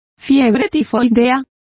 Complete with pronunciation of the translation of typhoid.